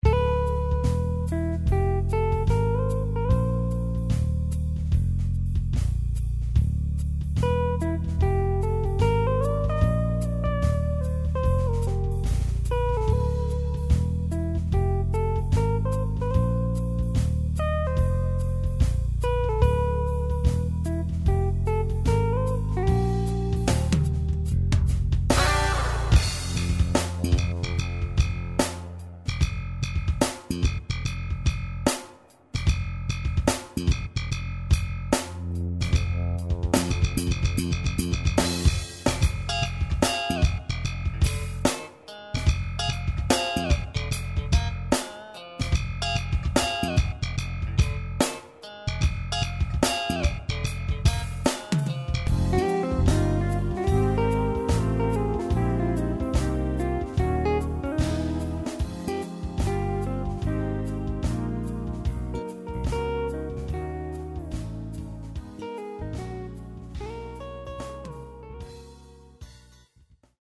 batterista